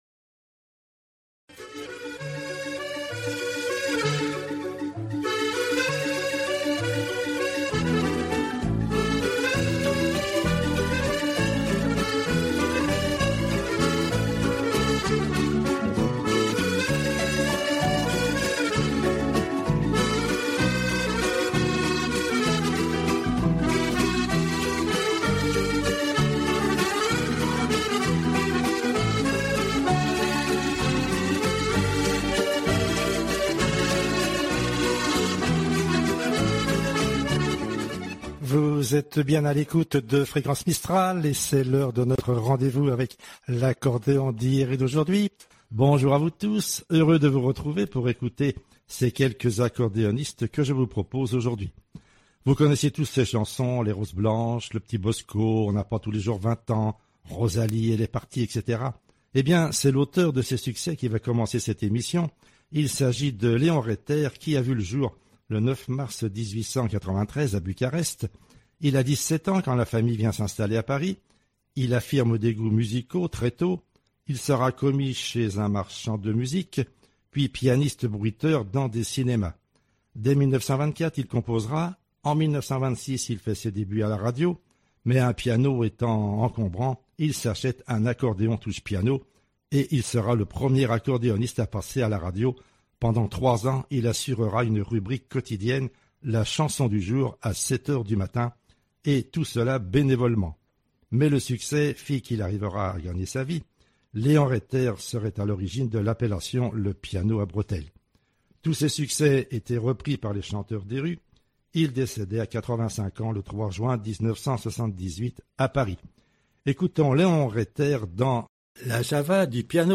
Accordéoniste finlandais : Medley de polkas